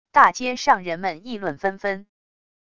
大街上人们议论纷纷wav音频